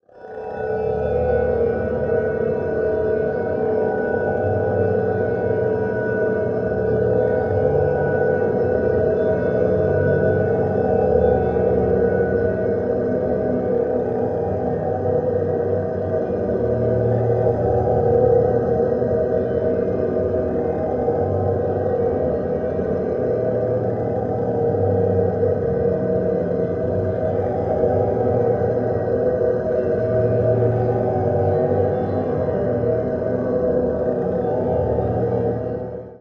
Mirror reverberate ringing, low echoing groans